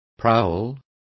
Complete with pronunciation of the translation of prowl.